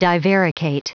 Prononciation du mot divaricate en anglais (fichier audio)
Prononciation du mot : divaricate